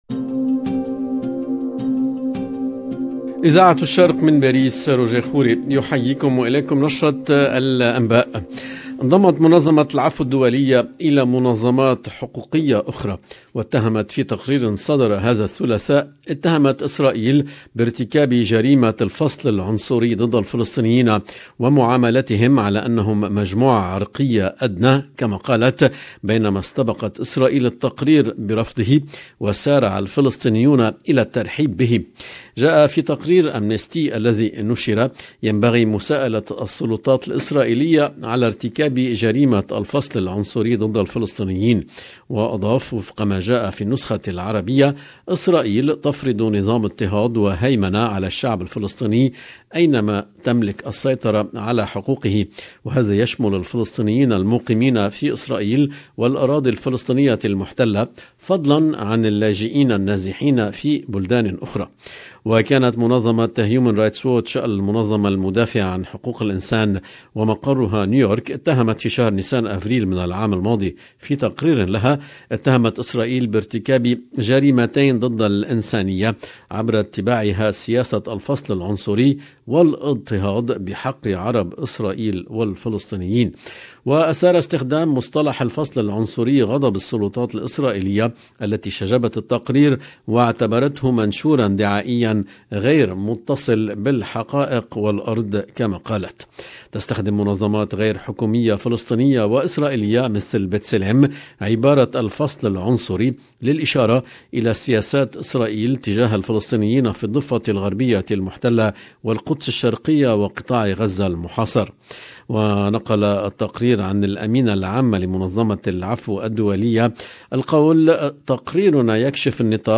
LE JOURNAL DU SOIR EN LANGUE ARABE DU 1/02/22